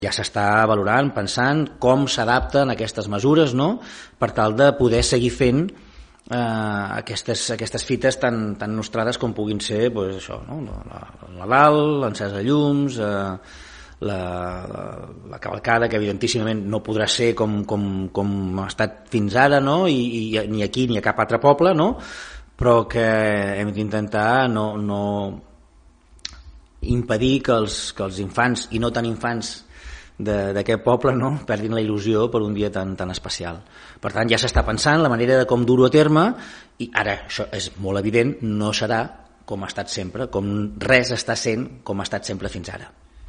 Entre altres, el consistori ja està valorant com adaptar les activitats de Nadal a les mesures que, en el seu moment, s’estiguin aplicant des de les autoritats pertinents. L’alcalde de Palafolls explica que la intenció és impedir que es perdi la il·lusió, especialment la dels més petits, pel que fa a la celebració d’aquestes properes festes nadalenques, però admet que enguany tot plegat no podrà celebrar-se amb la mateixa normalitat que anys anteriors.